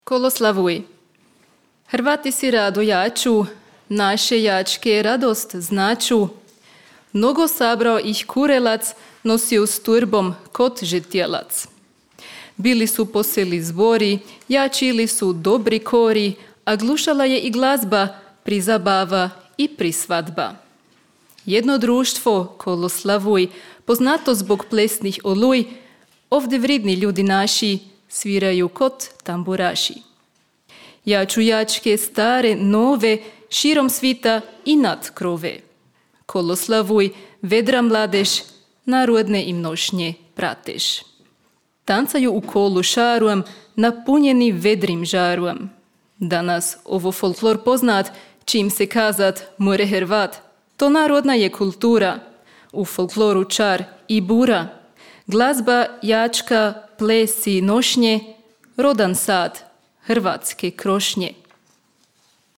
Leopold-maraton 1